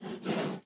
Minecraft Version Minecraft Version 1.21.4 Latest Release | Latest Snapshot 1.21.4 / assets / minecraft / sounds / mob / panda / nosebreath2.ogg Compare With Compare With Latest Release | Latest Snapshot
nosebreath2.ogg